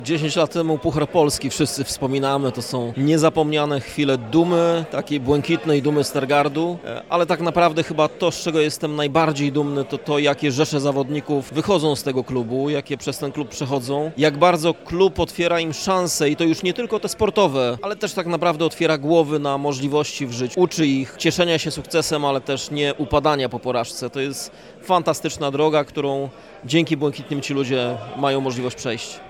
Z tej okazji odbyła się uroczysta gala, na której przypomniano najważniejsze momenty w historii klubu.
mówił prezydent Stargardu, Rafał Zając.